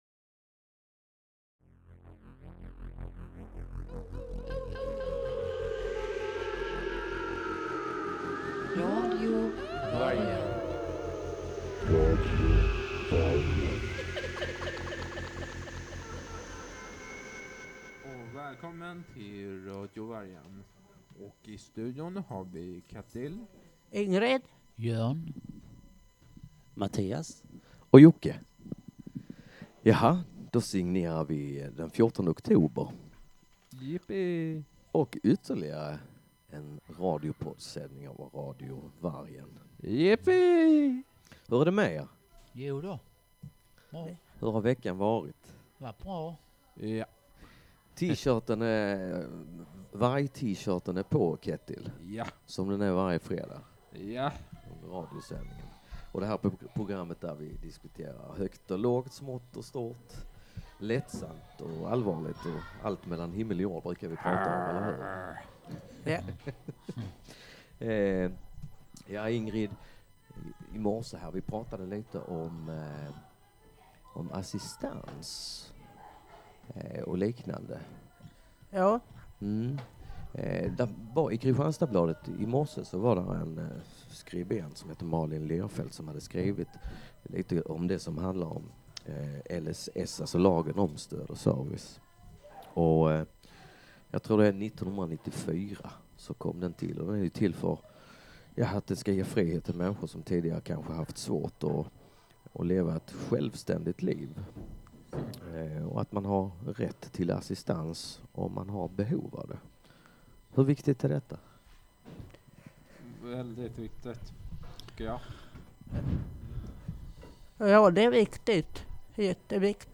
Idag har vi fint besök i studion.